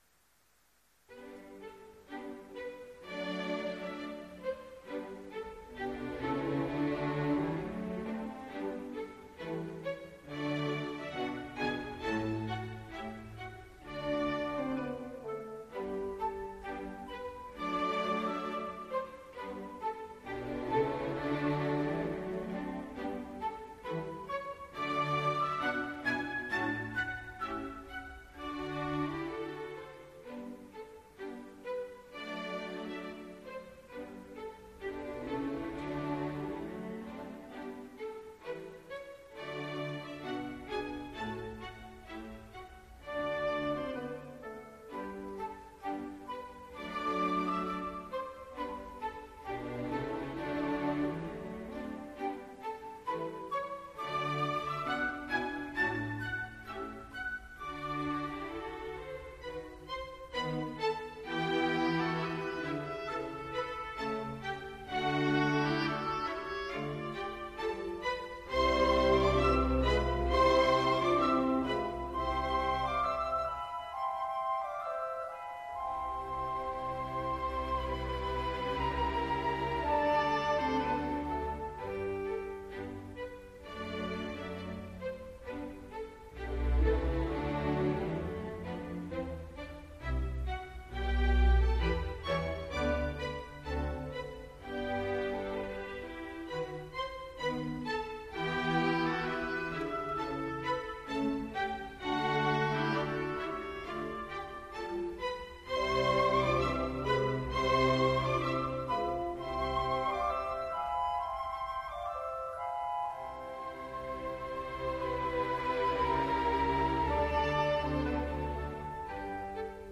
虽然录音有点干涩，但整体而言仍然优秀。